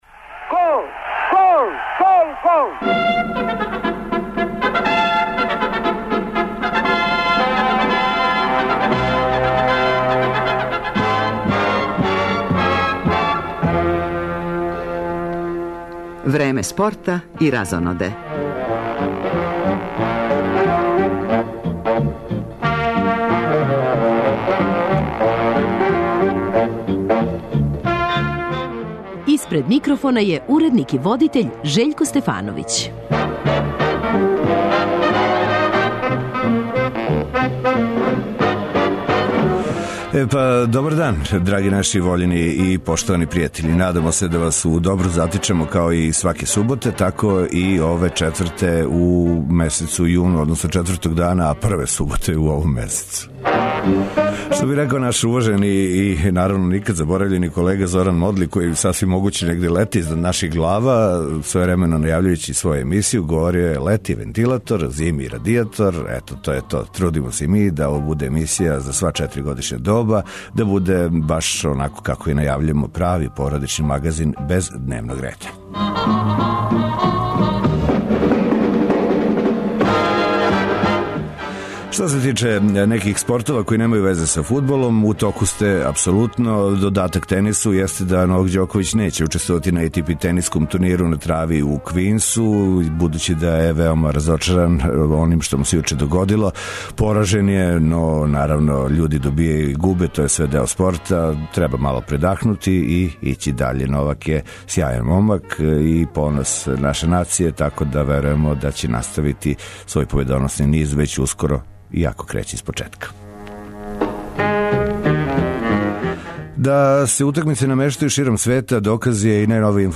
Радио Београд 1, 15.30